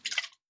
Surf Perc.wav